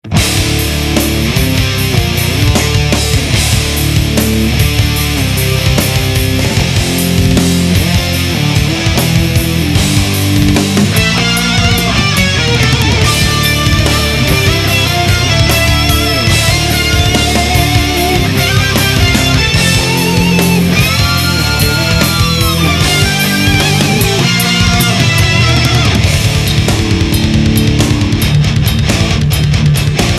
All the samples on this page are 22khz/44khz,16bit,stereo.